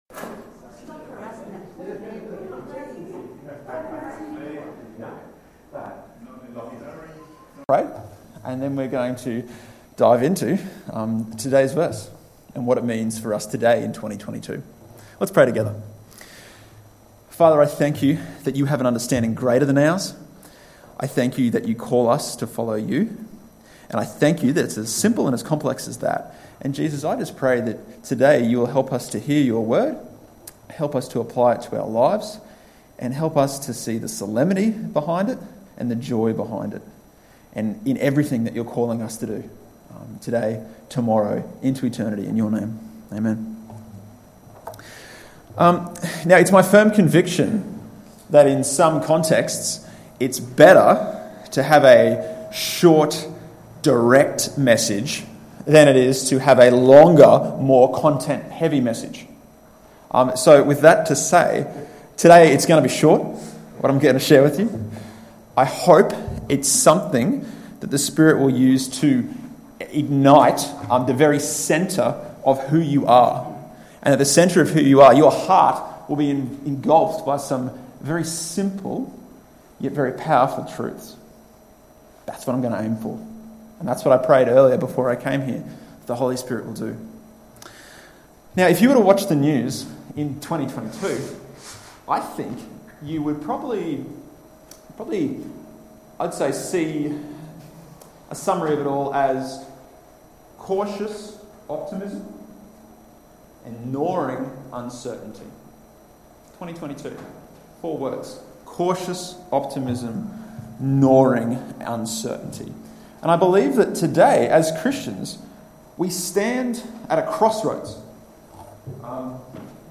Sermons | Tenthill Baptist Church
30/10/2022 Sunday Service